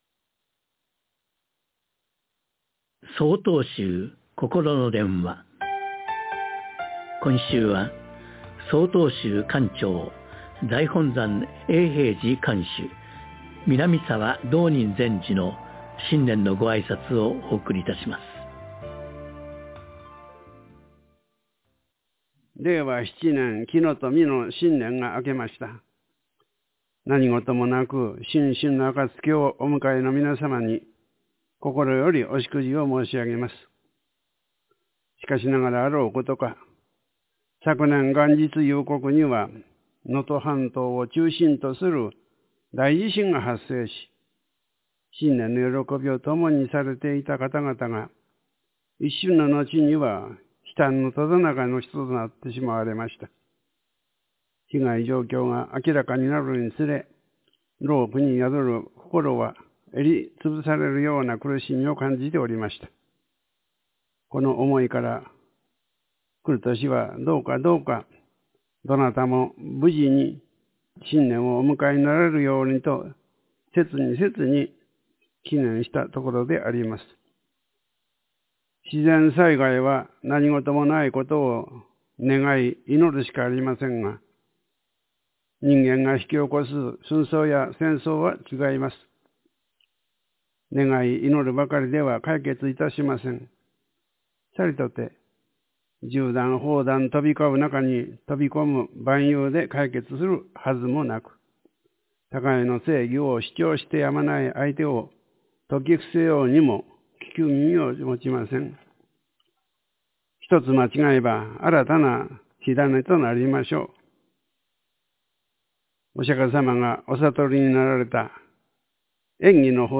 令和７年　年頭の挨拶
曹洞宗管長　南澤道人
ラジオNIKKEI　2025.1.1　放送　「声の年賀」より